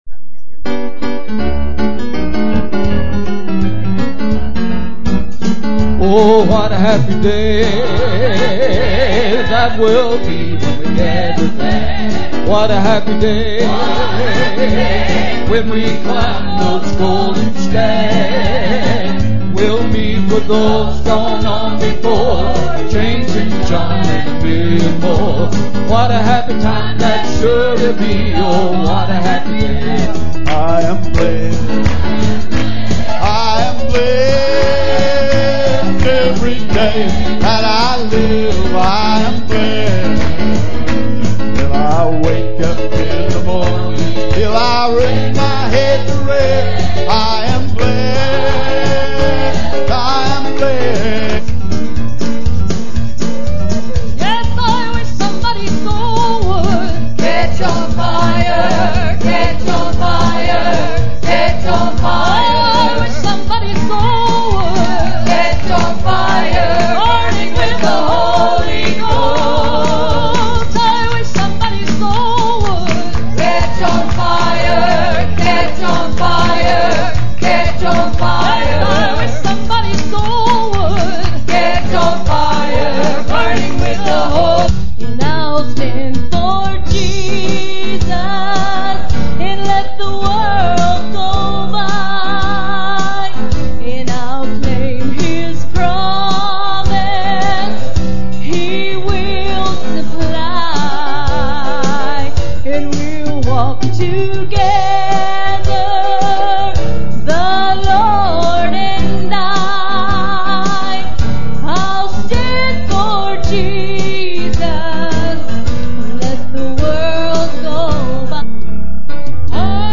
• Recorded LIVE!